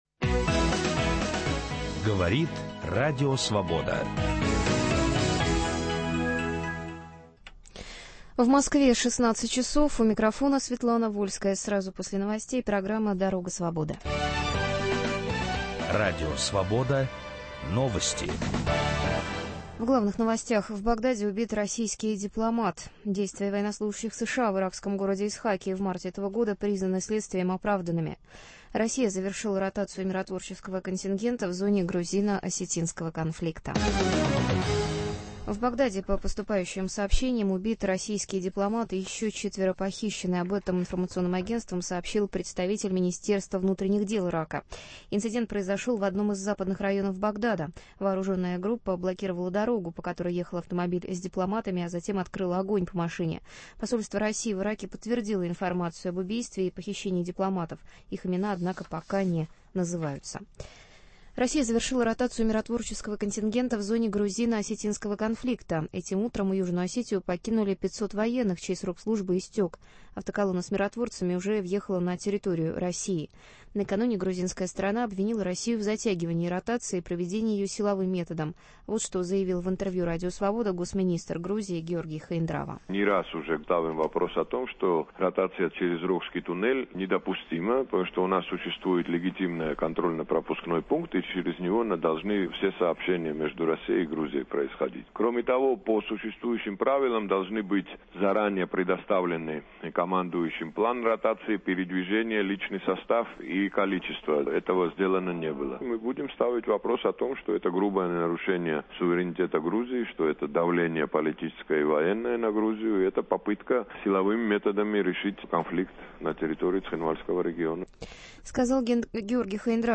Новый нацпроект Путина - стимулирование рождаемости и помощь молодым мамам. Как нарушаются права этих мам властями на местах, почему новые государственные программы помощи неэффективны и возможно ли победить демографический кризис рождаемостью, как думает российский президент, - об этом врач, демограф и простая мама троих детей.
Репортажи из регионов.